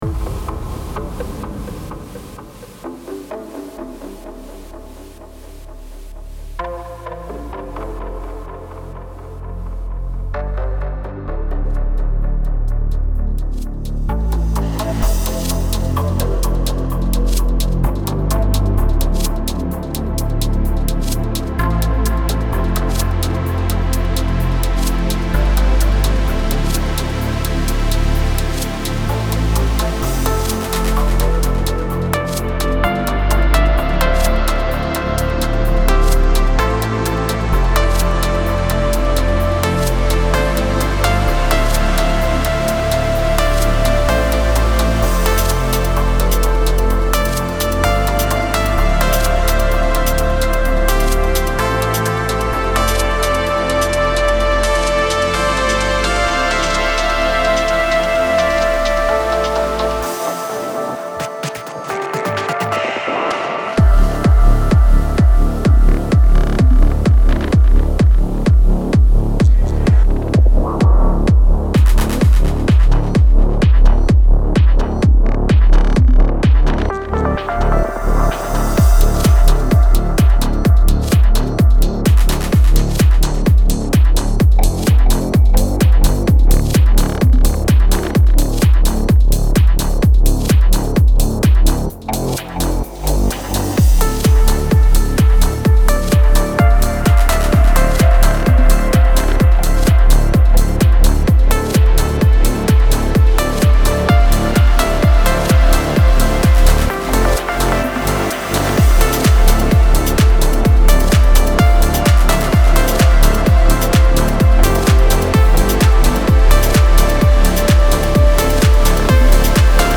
Демка на оценку
Стиль наверное progressive house Всего 2 синтезатора использовано и никаких +100500 плагинов) Еще не доделал...
Бочку поменял, бас немного сузил (но не сильно), пока так оставлю.